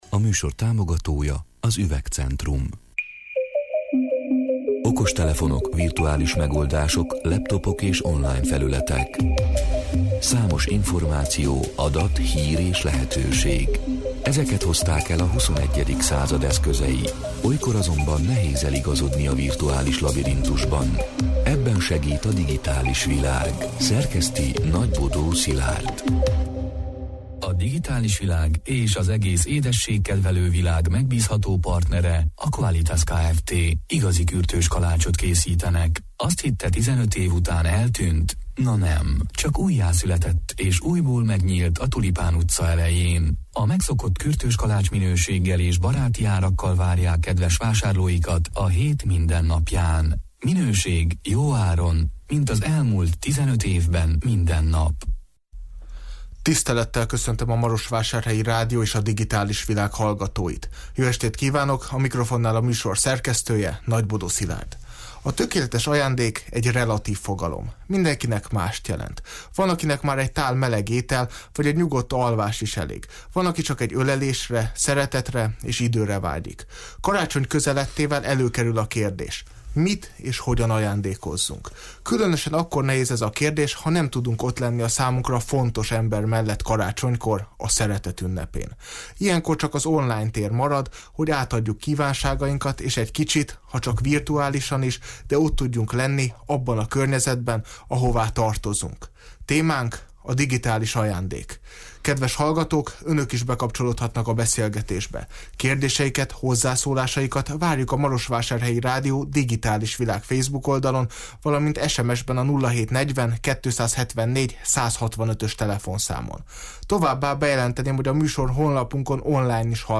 (Elhangzott: 2021. december 21-én, 20 órától élőben)